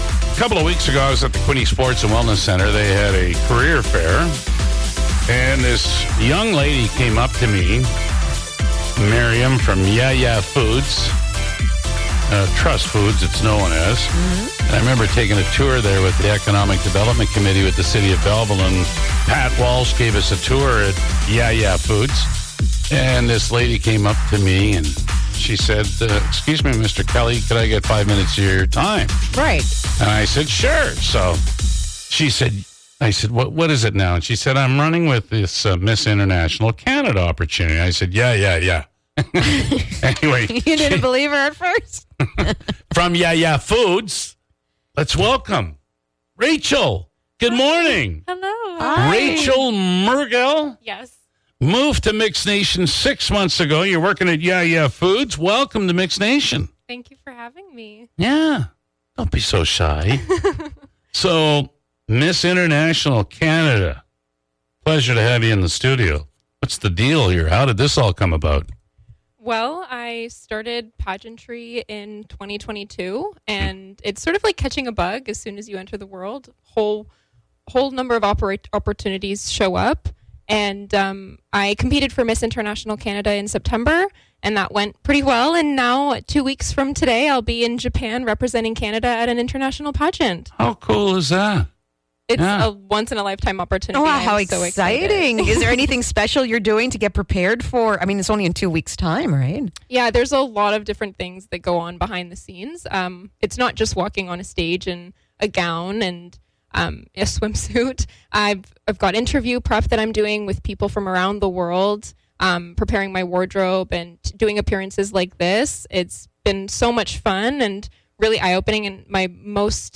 Belleville, ON, Canada / Mix 97